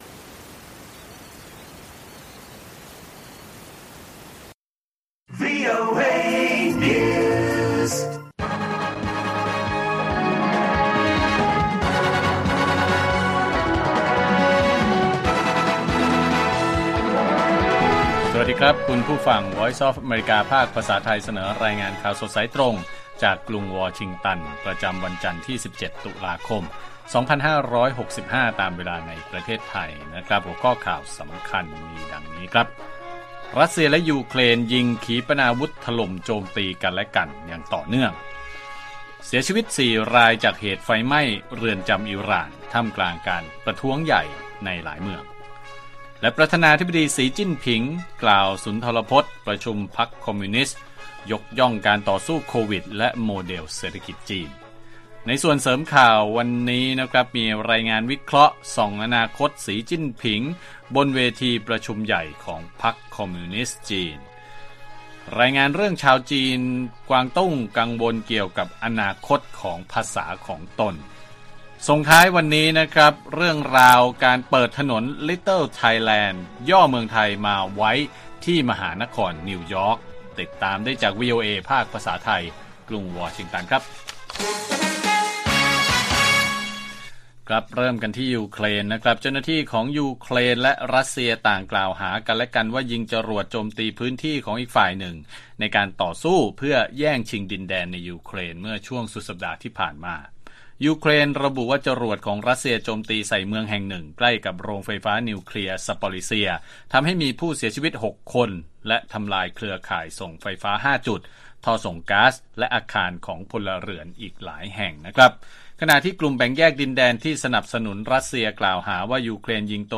ข่าวสดสายตรงจากวีโอเอ ภาคภาษาไทย 6:30 – 7:00 น. วันจันทร์ ที่ 17 ต.ค. 2565